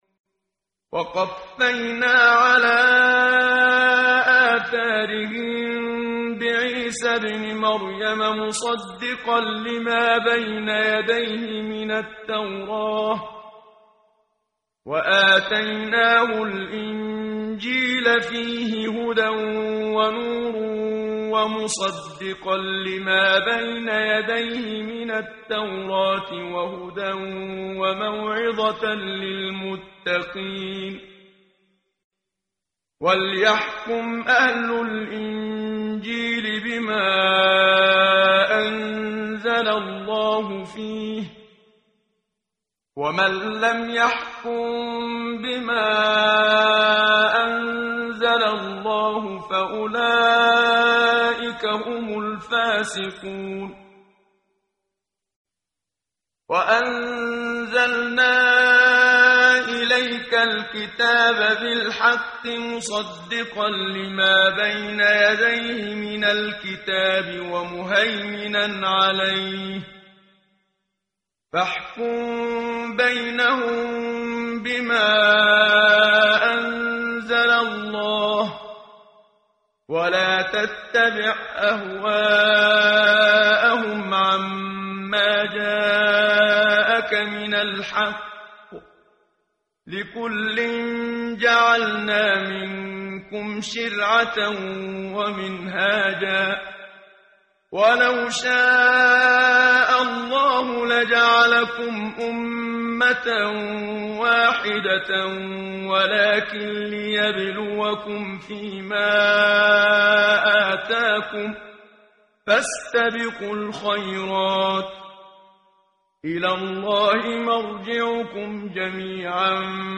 ترتیل صفحه 116 سوره مبارکه المائده (جزء ششم) از سری مجموعه صفحه ای از نور با صدای استاد محمد صدیق منشاوی